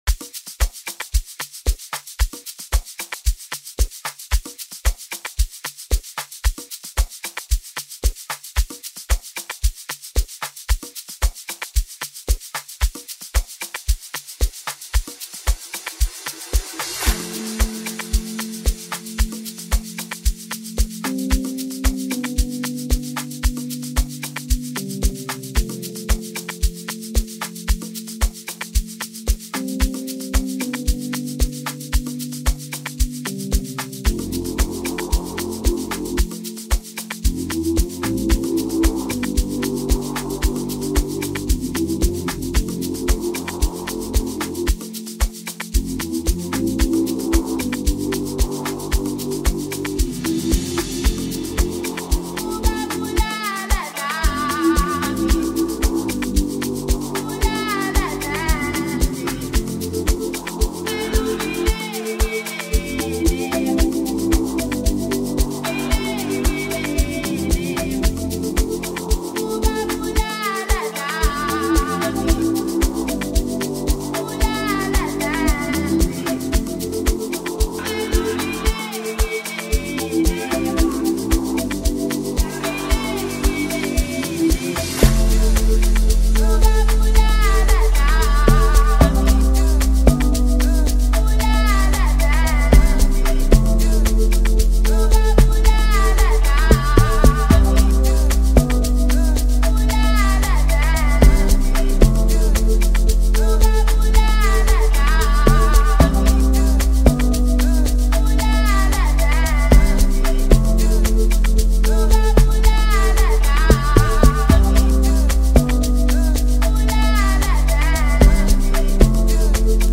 fresh and catchy Amapiano track